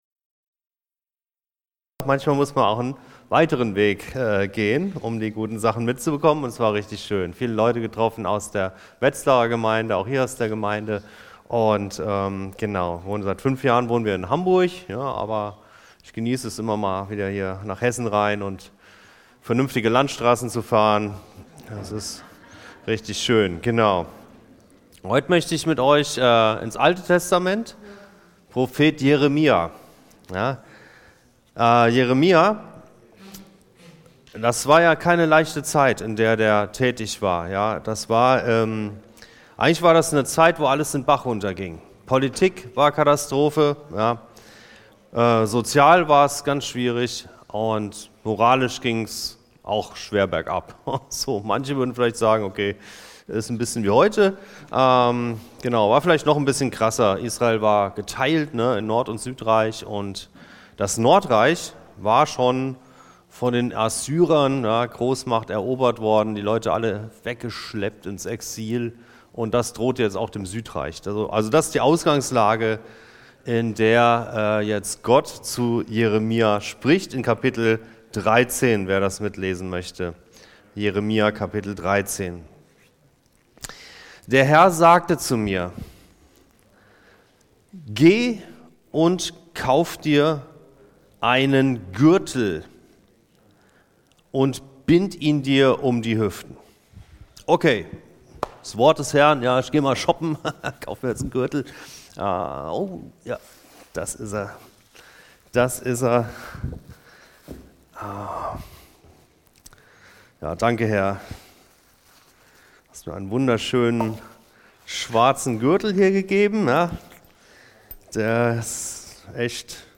In Jeremia 13,1–11 gebraucht Gott das Bild eines Gürtels, der vermodert und unbrauchbar geworden ist – ein Gleichnis für Israels Hochmut und seine Abkehr von Gott. Das Volk hat seine eigentliche Bestimmung verloren: Gott nahe zu sein und ihm zur Ehre zu leben. In seiner Predigt vom 28. September 2025